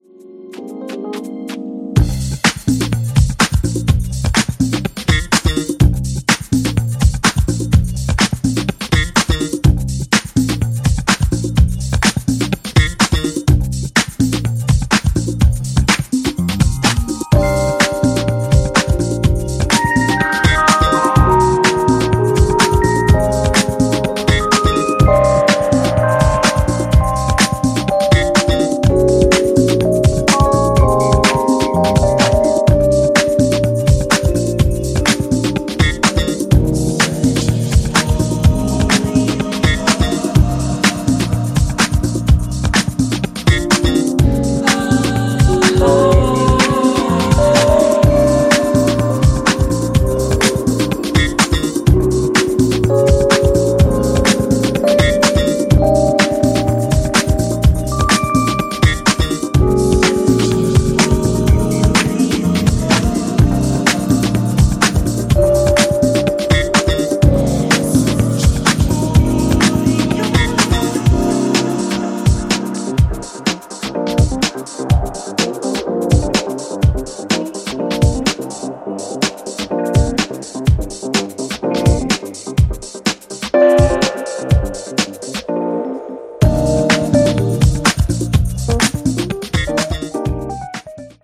ジャンル(スタイル) HOUSE / CROSSOVER